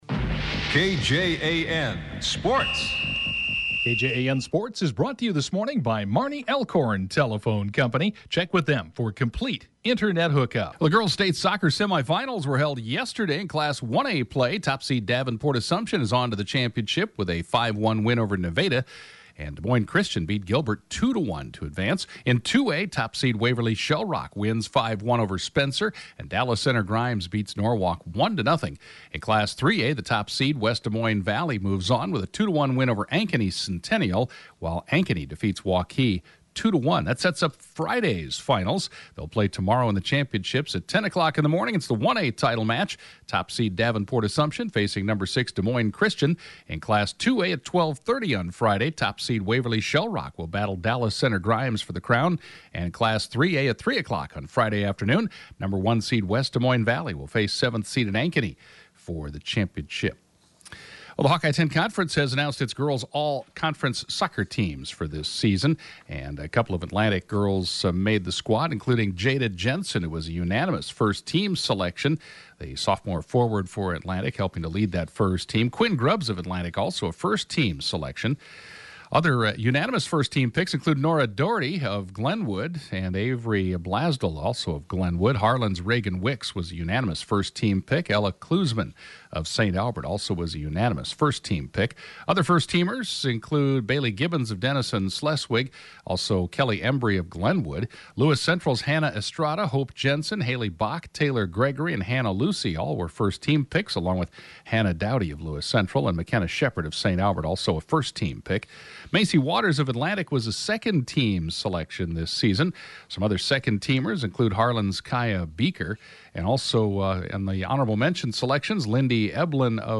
(Podcast) KJAN morning Sports report, 6/10/21